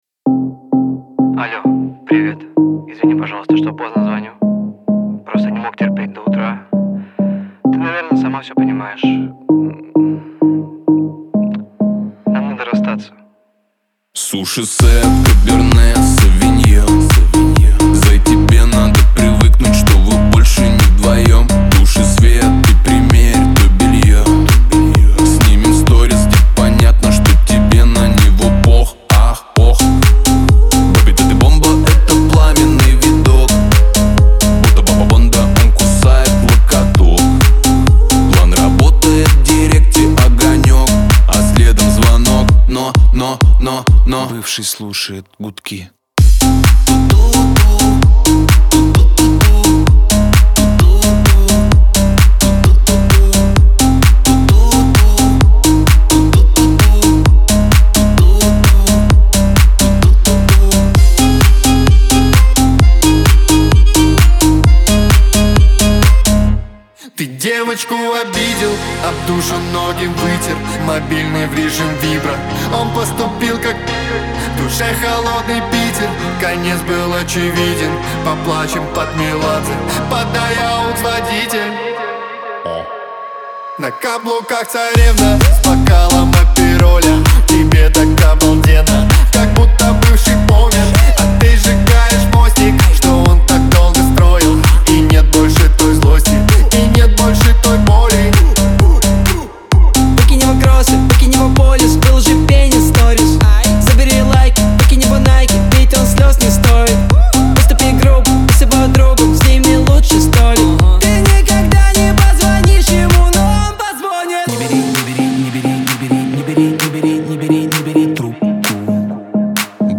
который сочетает в себе элементы рэпа и поп-музыки.